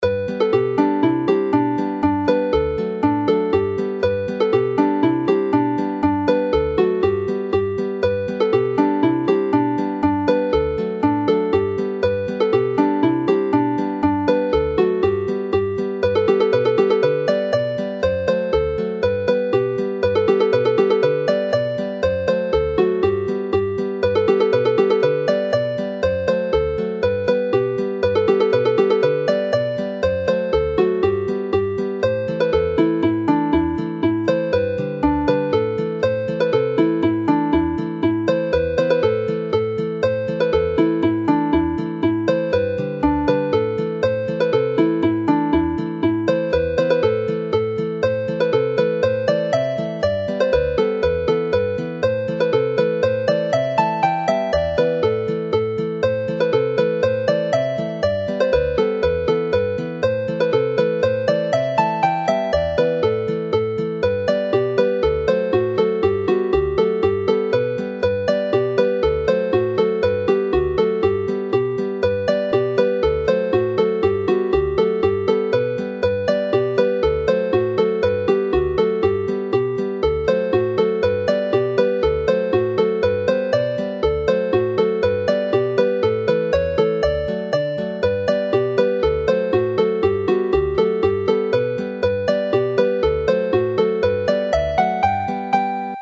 Set y Bois - (polcas)